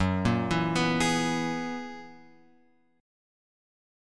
F# 11th